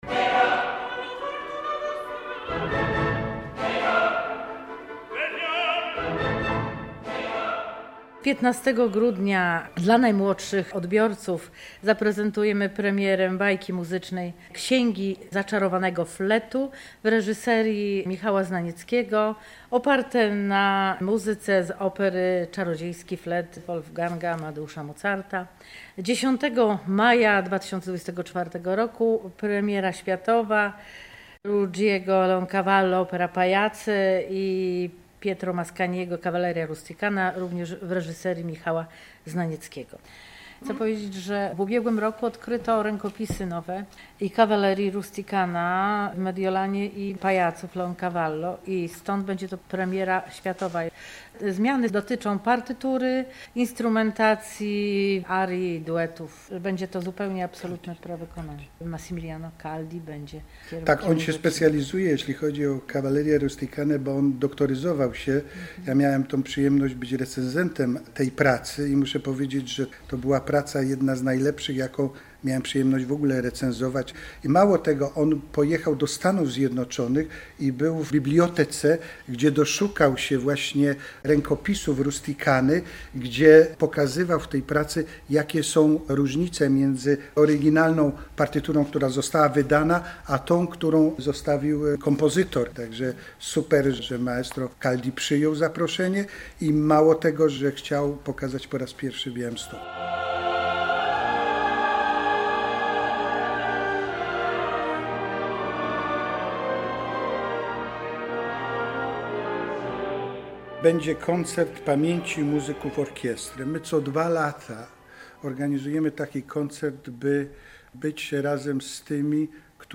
Relację nie bez kozery zakończyliśmy muzyką z "Upiora w operze", bowiem białostocka opera wznowić ma ten słynny musical.